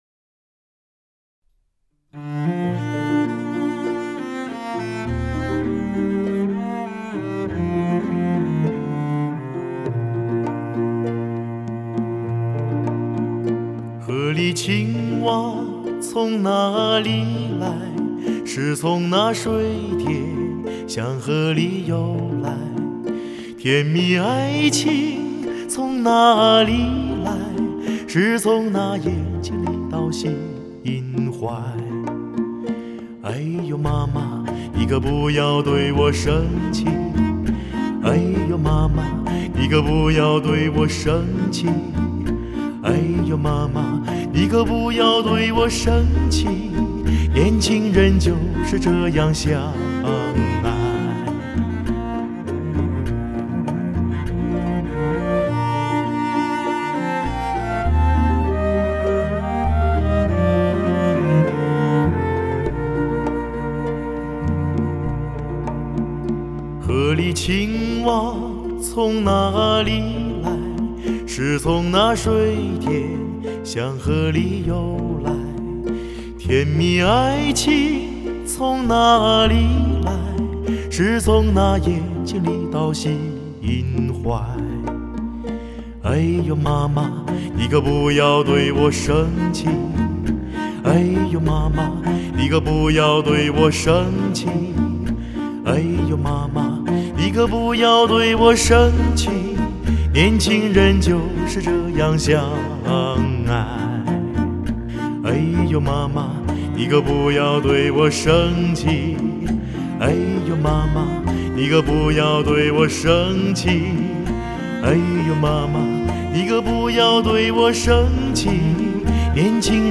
该专辑以旧歌新唱的歌曲为主